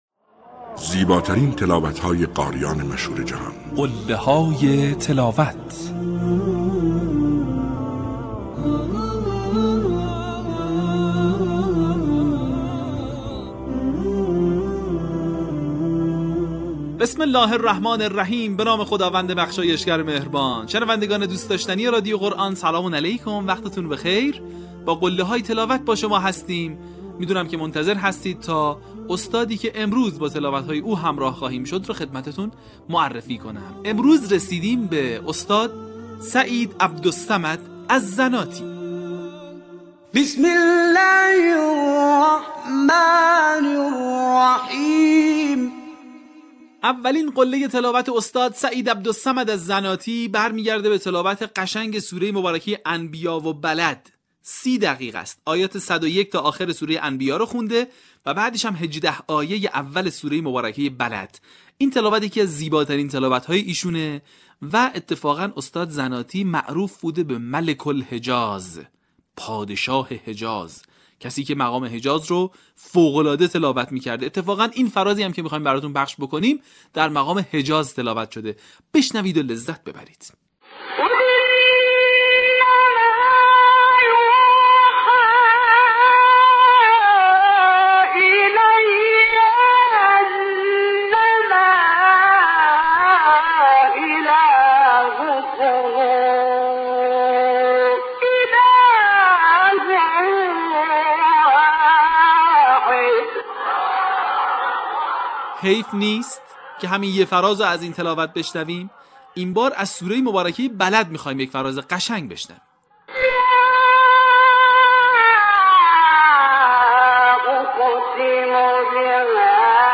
در قسمت بیست‌وهشتم فراز‌های شنیدنی از تلاوت‌های به‌یاد ماندنی استاد سعید عبدالصمد الزناتی را می‌شنوید.
برچسب ها: سعید عبدالصمد الزناتی ، قله های تلاوت ، تلاوت ماندگار ، تلاوت تقلیدی